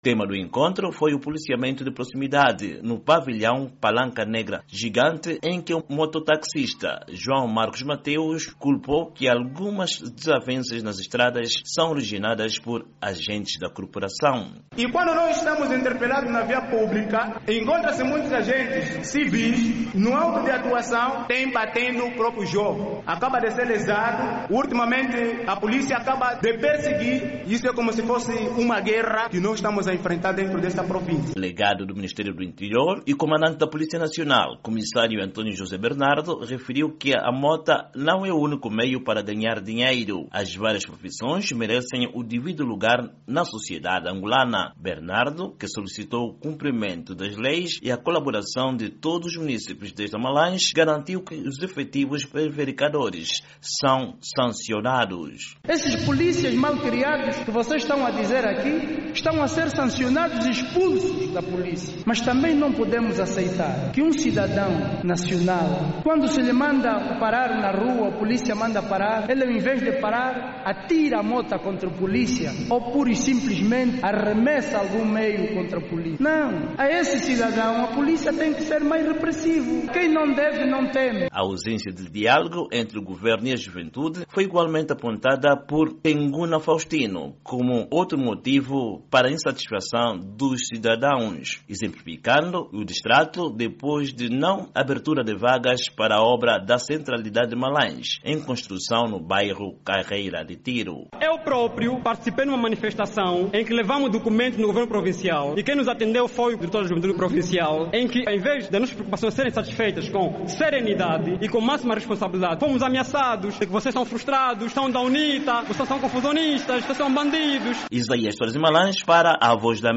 O descontentamento de moto-taxistas e de jovens em realção à actuação da polícia e das autoridades governamentais de Malanje esteve em foco numa reunião recente no pavilhão Palanca Negra, na capital daquela província angolana.